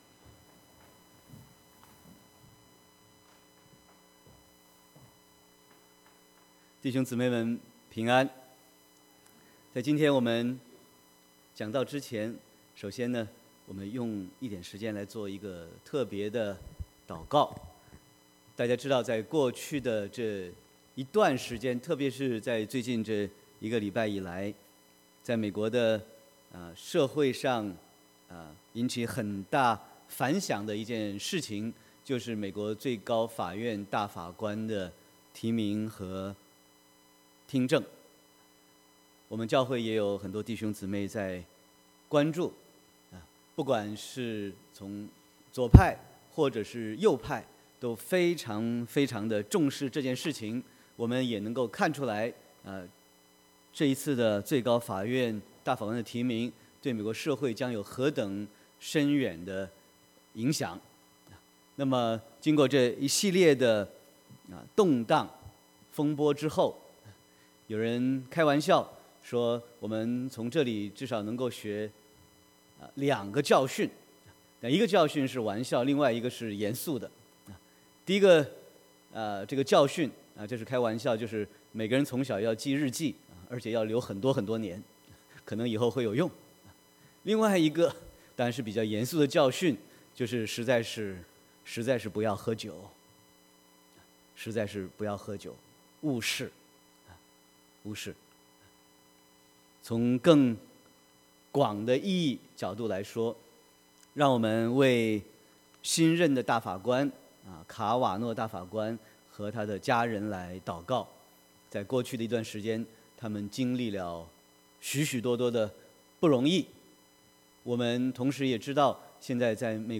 Sermon 10/07/2018